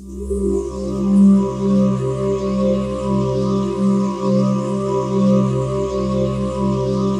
PAD 49-1.wav